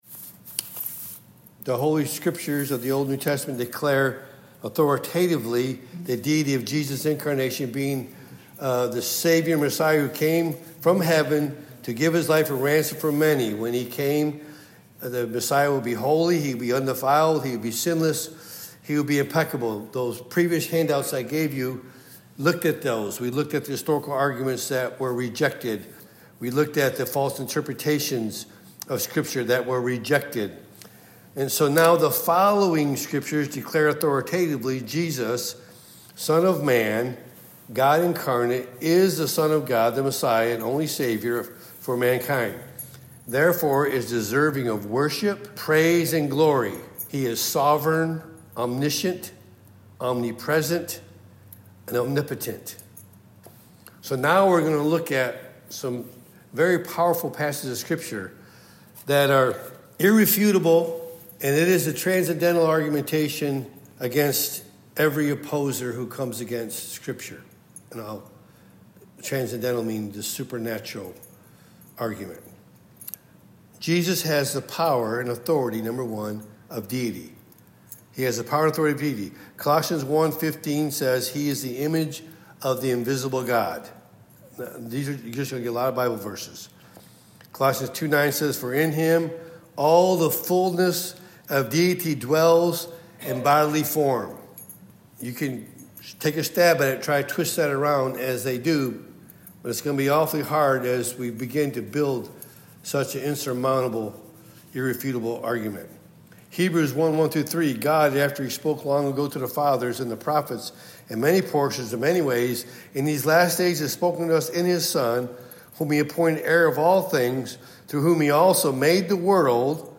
Topic: Wednesday Pastoral Bible Study